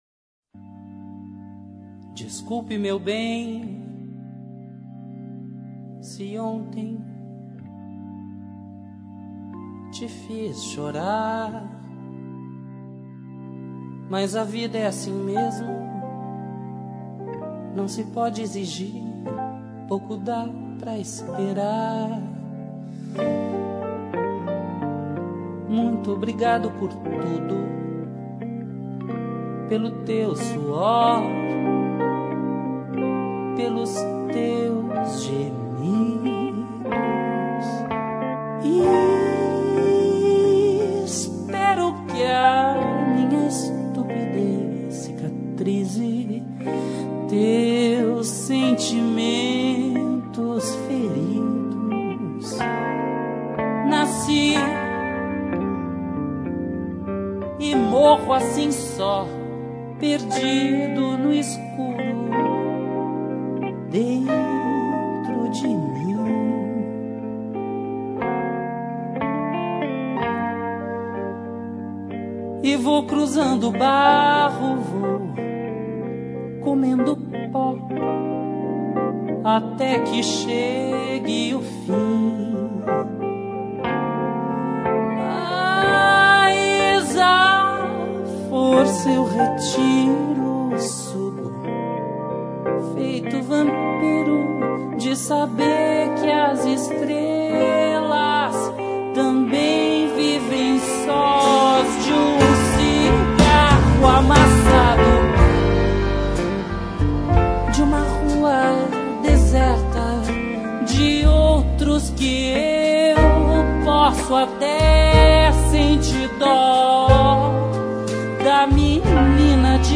Boleros